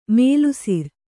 ♪ mēlusir